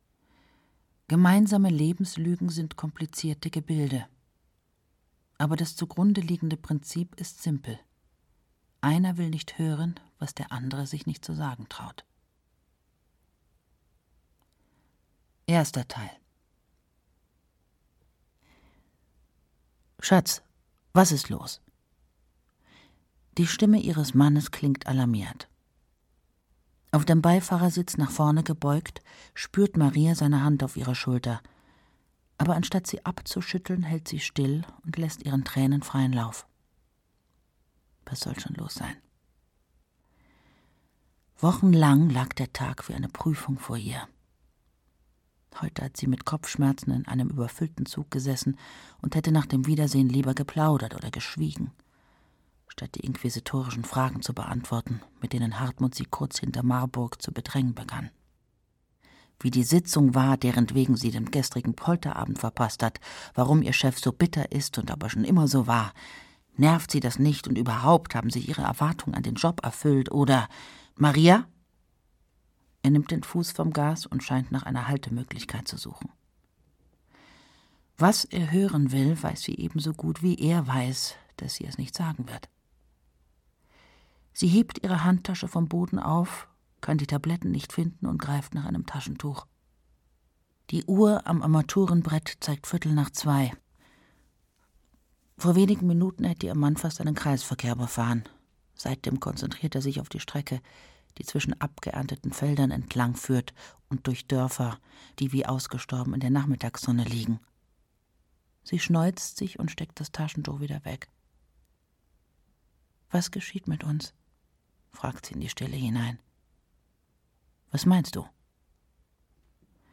Gegenspiel - Stephan Thome - Hörbuch